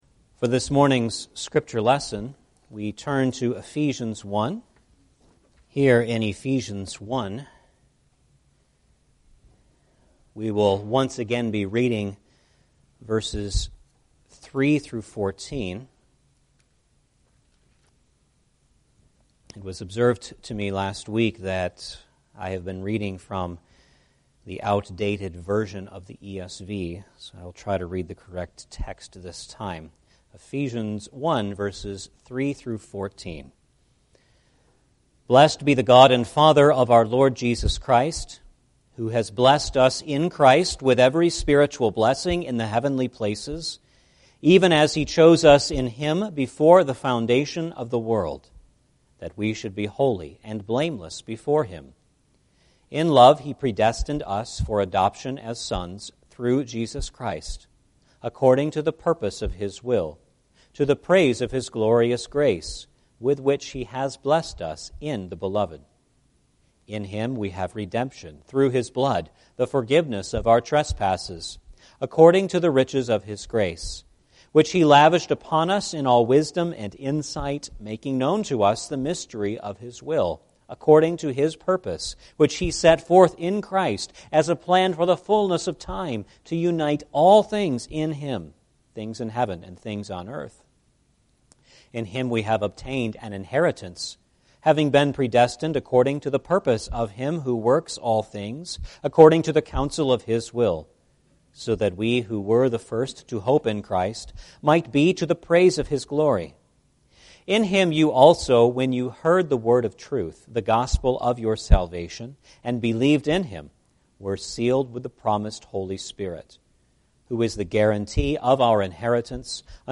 Ephesians Passage: Ephesians 1:11-14 Service Type: Sunday Morning Service « Eschatology #5 What is Man